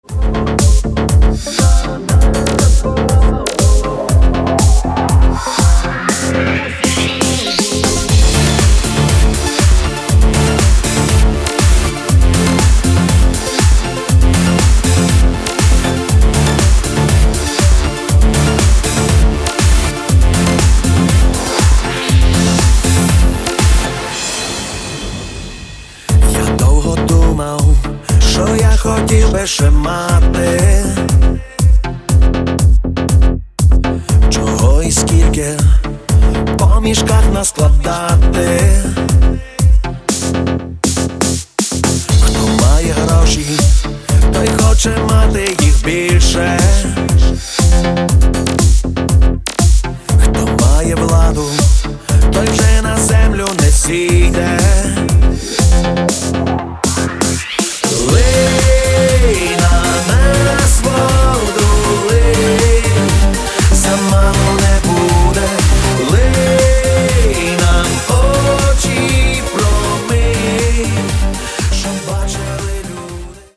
Каталог -> Поп (Легкая) -> Ремиксы
electro remix